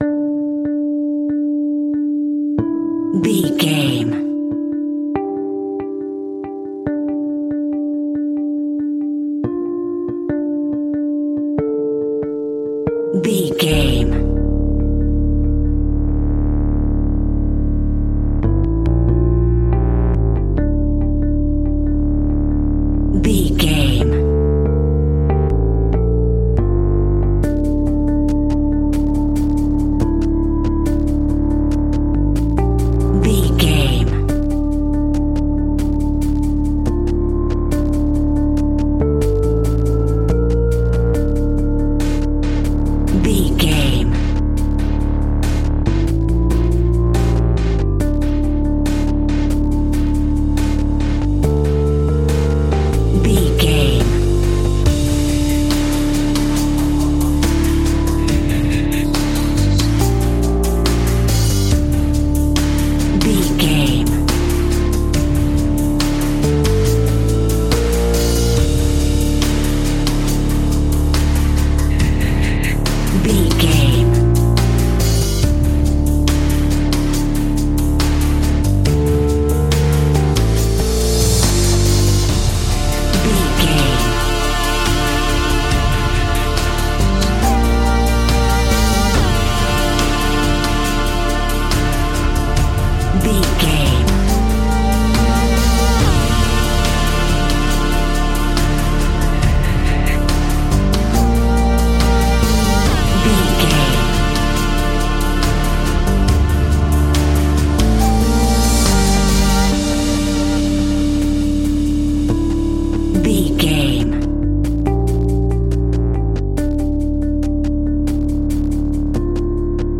royalty free music
Aeolian/Minor
D
scary
ominous
dark
disturbing
eerie
synthesiser
percussion
drums
ticking
electronic music
Horror Synths